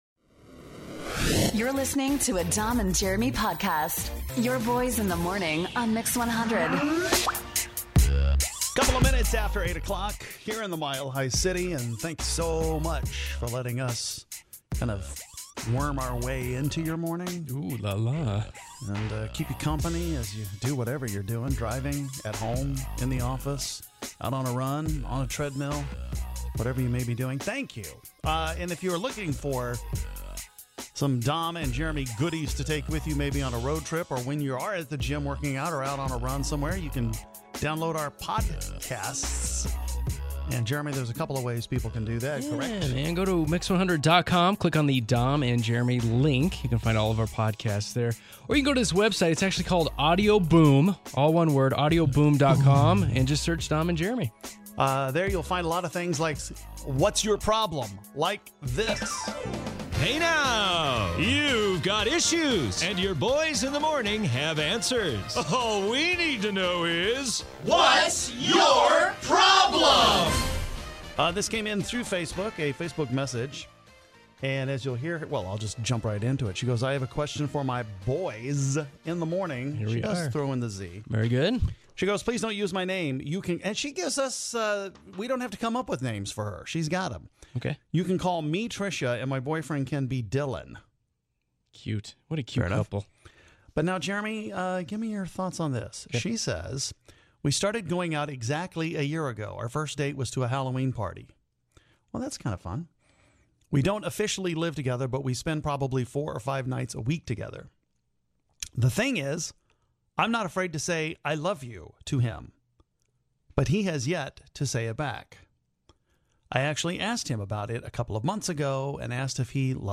There are always some things that annoy us in relationships, but some things are bigger than others. We discuss a big one today here and take five calls to say it all.